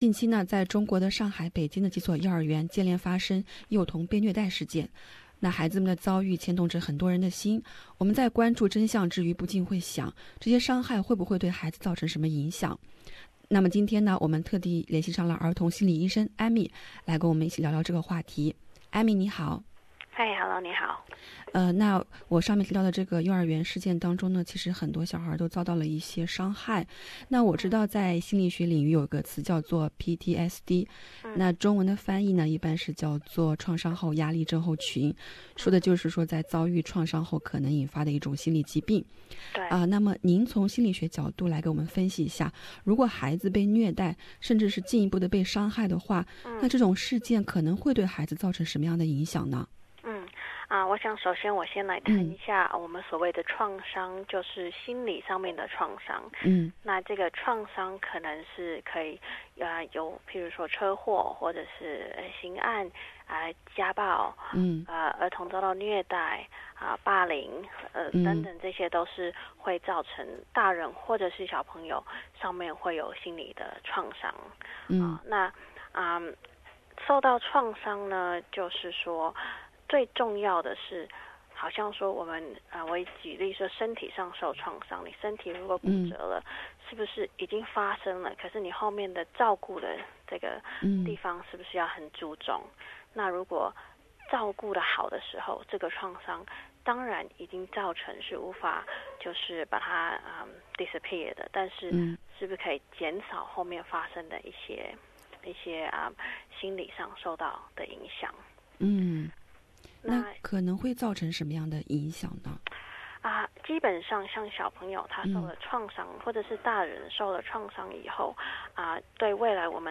采访中